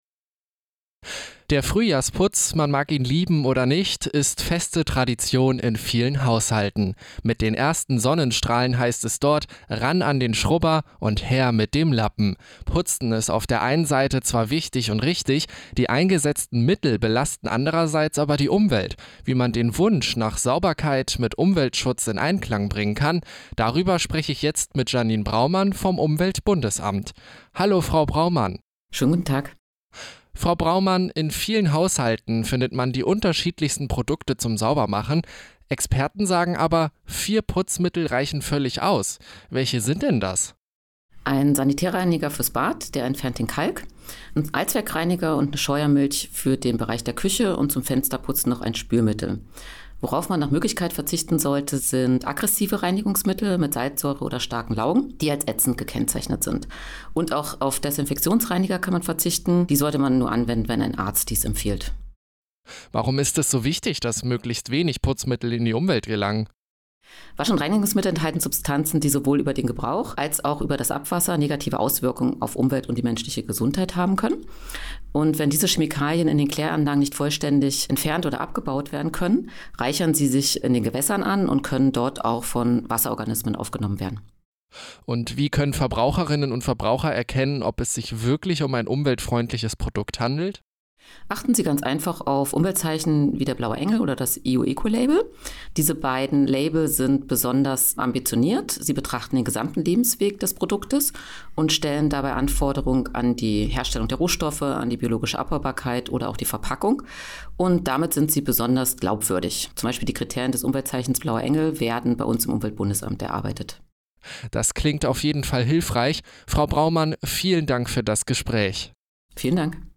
Glänzend und rein: Tipps für den umweltfreundlichen Frühjahrsputz. Ein Interview